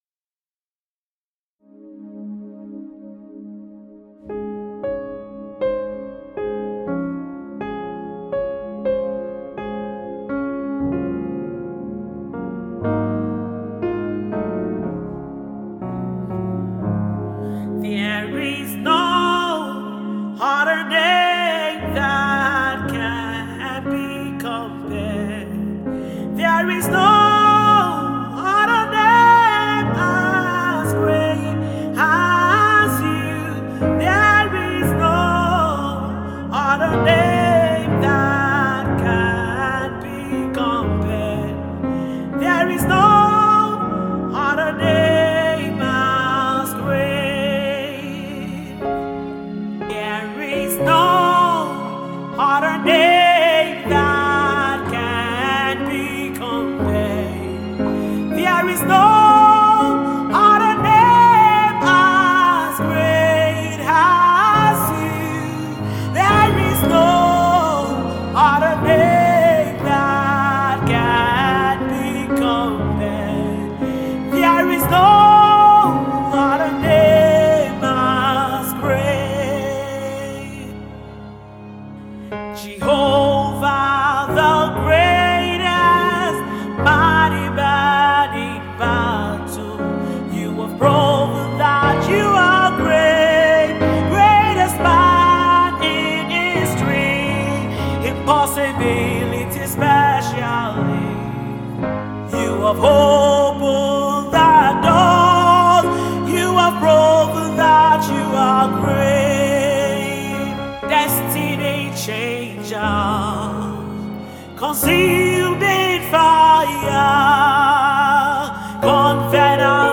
US-based Nigerian Gospel Singer
Beyond words, express your love to God in deep worship.